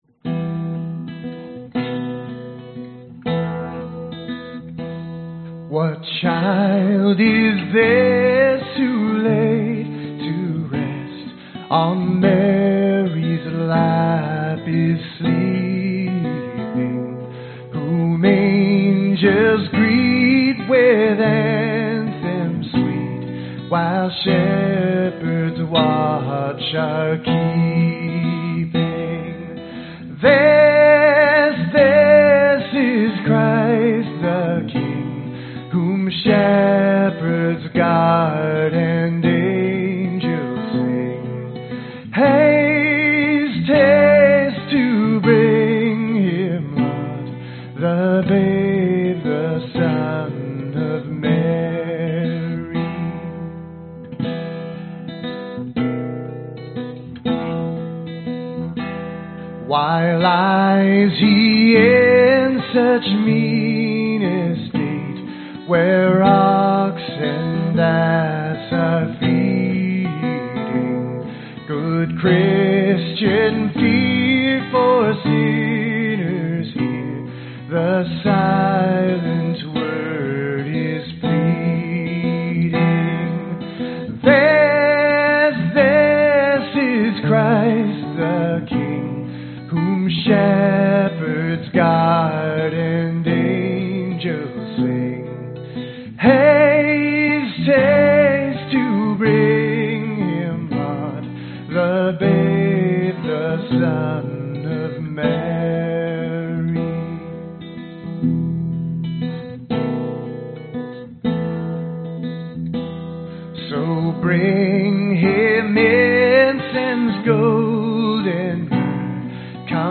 标签： 原声 环境 颂歌 圣诞 吉他 尼龙弦 圣诞
声道立体声